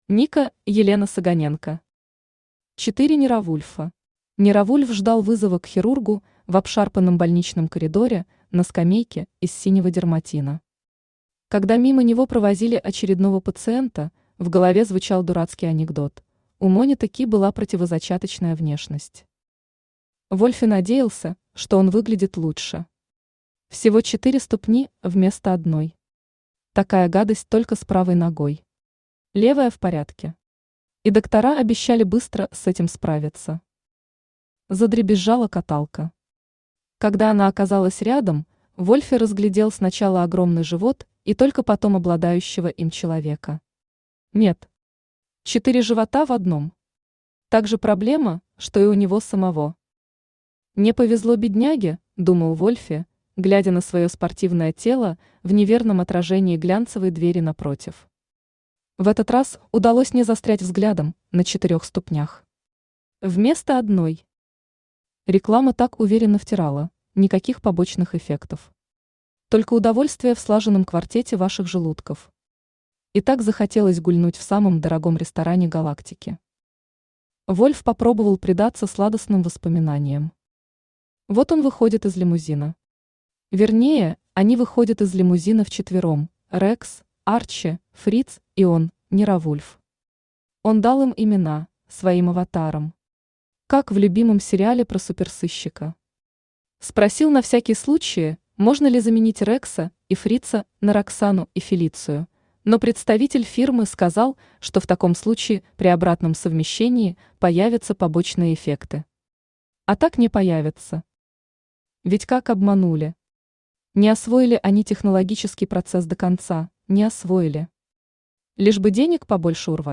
Aудиокнига Четыре Нировульфа Автор Ника-Елена Саганенко Читает аудиокнигу Авточтец ЛитРес.